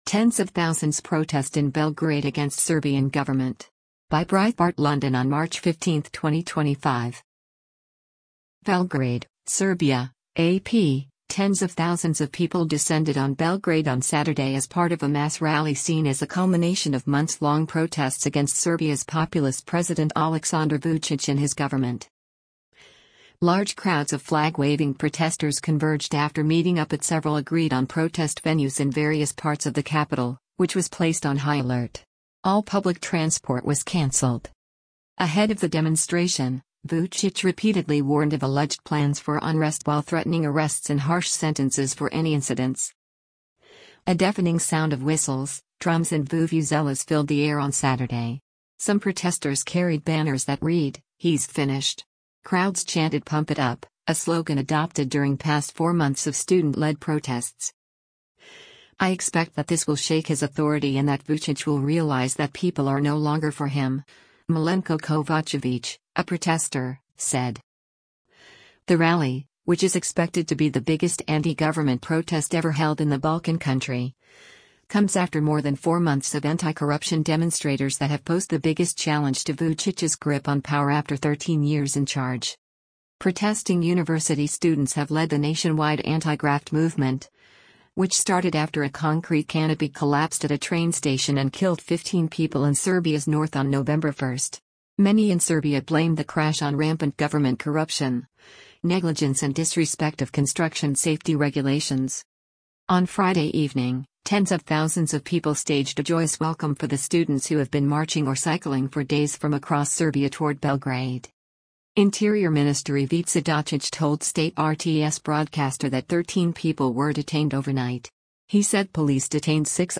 Large crowds of flag-waving protesters converged after meeting up at several agreed-on protest venues in various parts of the capital, which was placed on high alert.
A deafening sound of whistles, drums and vuvuzelas filled the air on Saturday.
Crowds chanted “Pump it Up,” a slogan adopted during past four months of student-led protests.